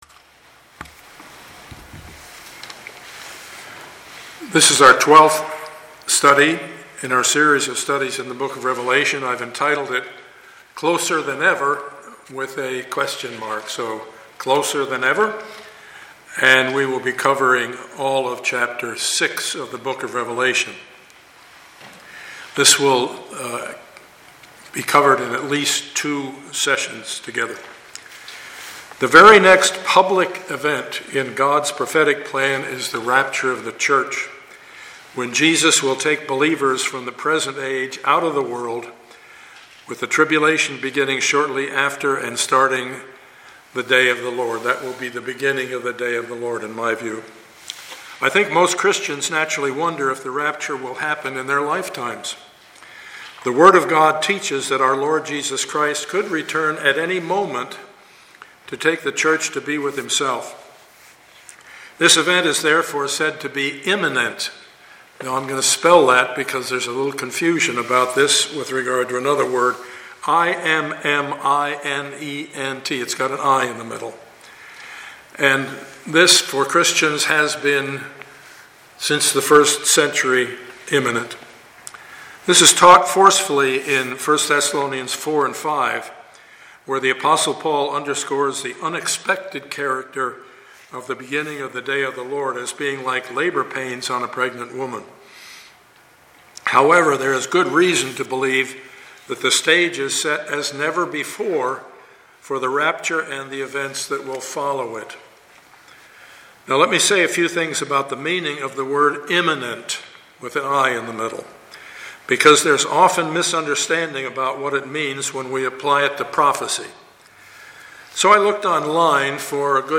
Passage: Revelation 6:1-17 Service Type: Sunday morning